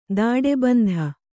♪ dāḍe bandha